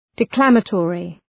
Shkrimi fonetik{dı’klæmə,tɔ:rı}
declamatory.mp3